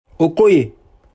PronunciationOH-KOH-YAY